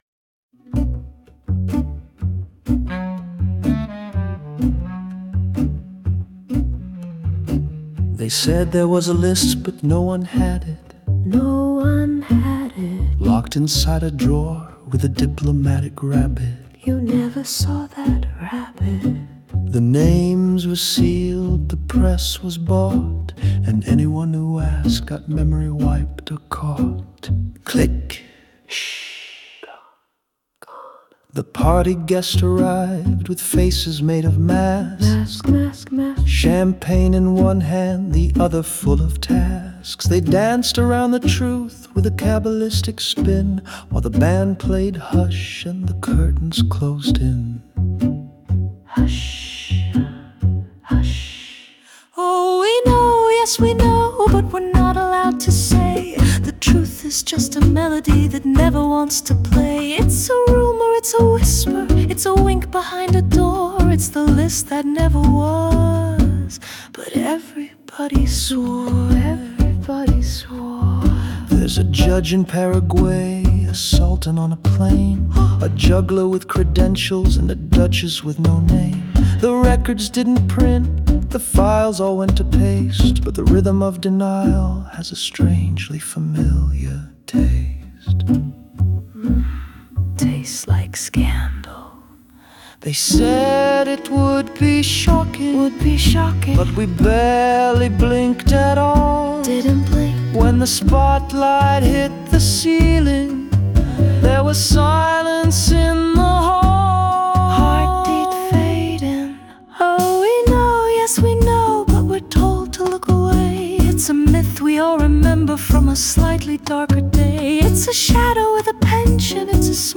The List That Never Was is a hard‑edged track about silence, power, and the ghosts of secrets that never see daylight.
The beat drives steady, like footsteps down a hallway you’re not supposed to walk. The lyrics cut close, circling the scandal that shook the world — and the names that never made it to print.